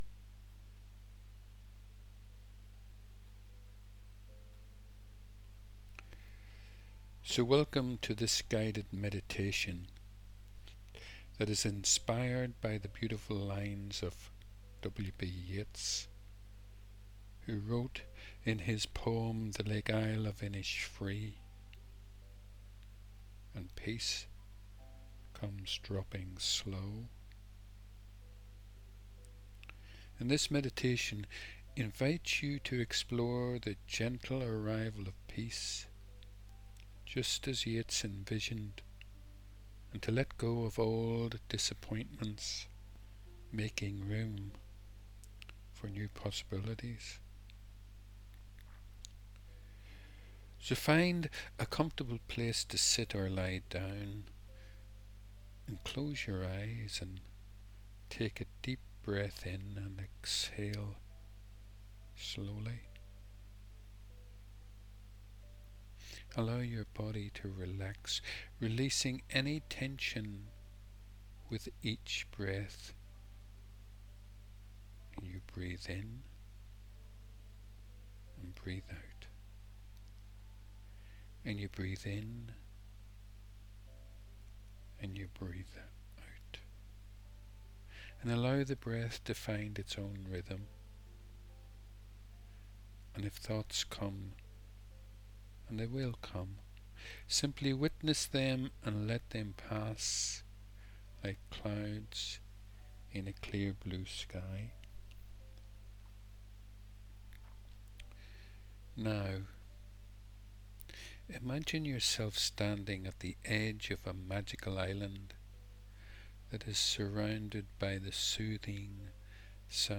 This guided meditation for inner peace recording and script is a fairly short guided meditation. I like to add a lot of silence at the end of any guided meditation to allow you to abide within the “Peace that comes dropping slow.”
Guided-Meditation-for-Inner-Peace.mp3